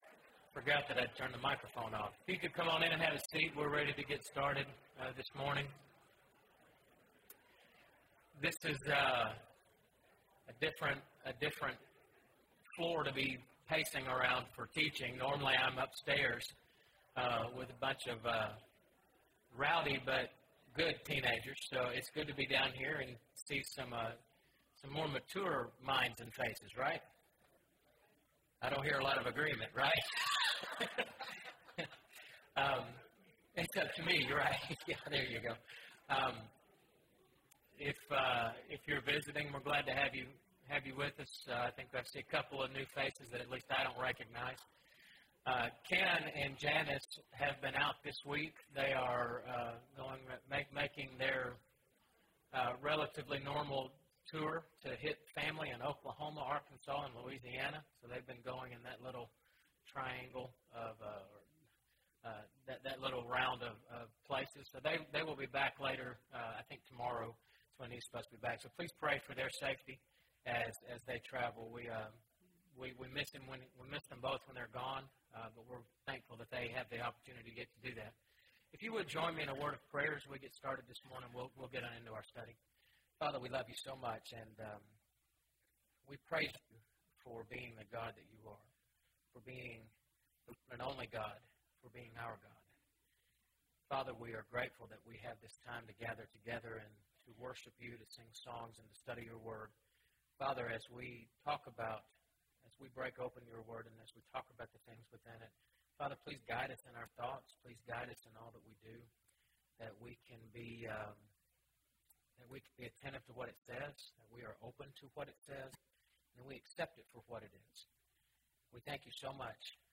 Romans – (Guest Speaker) – Bible Lesson Recording